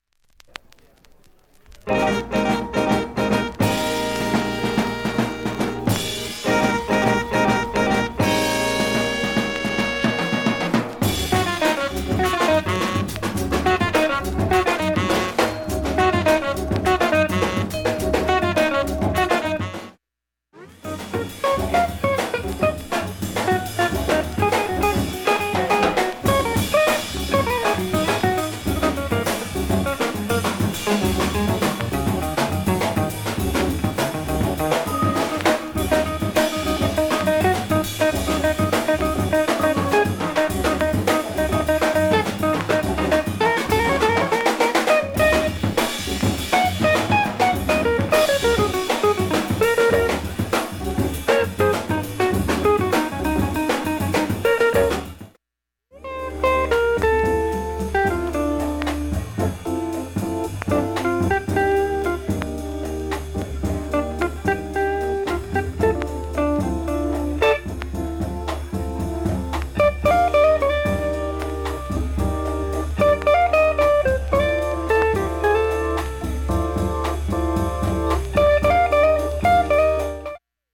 A-1始めにかすかなプツが８回と１回出ます。
A-1中盤にかすかなプツが１回と１回と８回出ます。
A-2中盤にかすかなプツが１１回出ます。